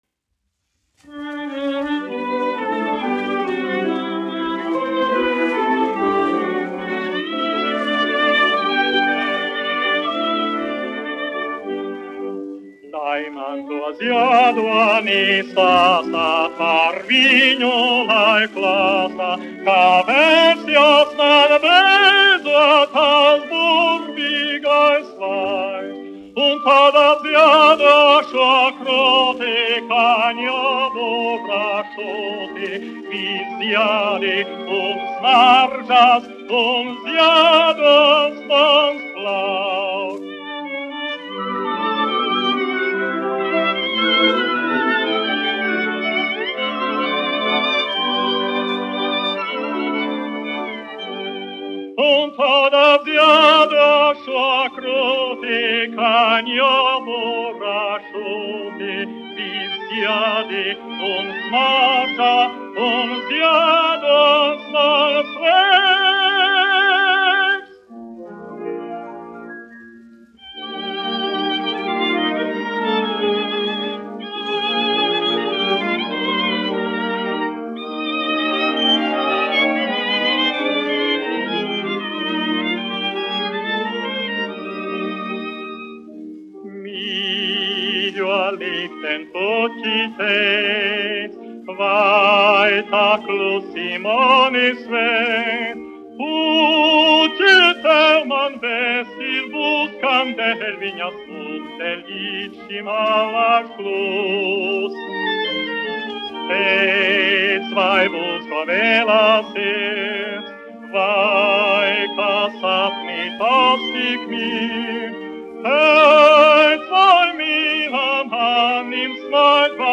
dziedātājs
1 skpl. : analogs, 78 apgr/min, mono ; 25 cm
Operetes--Fragmenti
Latvijas vēsturiskie šellaka skaņuplašu ieraksti (Kolekcija)